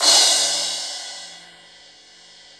Index of /90_sSampleCDs/Sound & Vision - Gigapack I CD 1 (Roland)/CYM_K-CRASH st/CYM_K-Crash st 2
CYM CRA3203R.wav